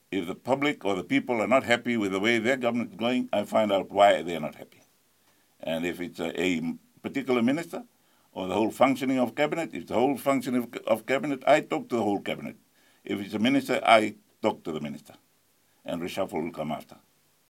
Speaking during a press briefing yesterday where he made his stance on the matter of a casino supposedly to be built in Nadi, Rabuka was asked if he is still considering doing a reshuffle within his cabinet.